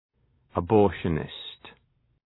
{ə’bɔ:rʃənıst}
abortionist.mp3